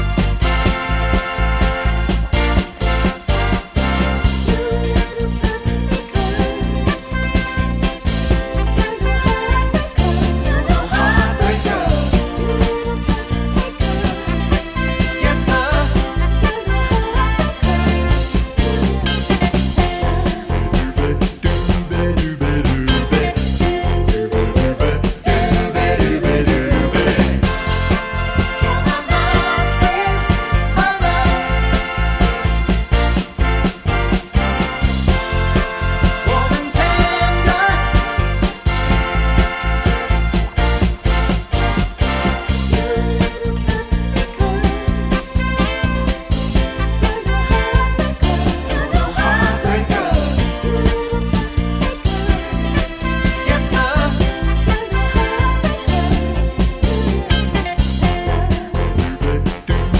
no chorus BV Soul / Motown 2:45 Buy £1.50